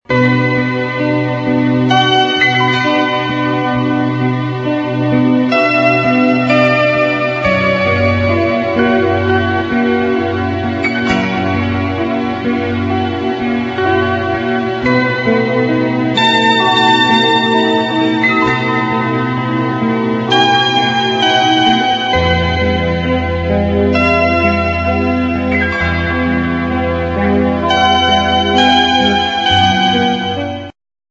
sad slow instr.